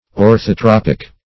Search Result for " orthotropic" : The Collaborative International Dictionary of English v.0.48: Orthotropic \Or`tho*trop"ic\, a. [See Orthotropal .]